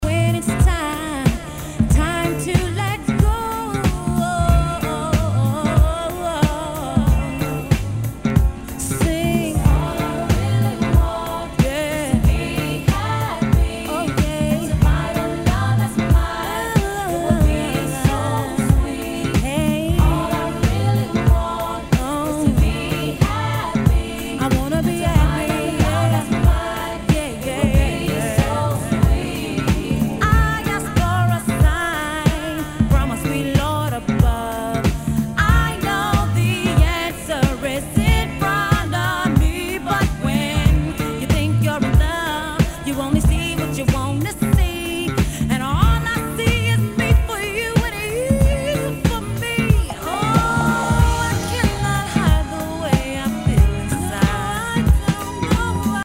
HIPHOP/R&B
R&Bクラシック！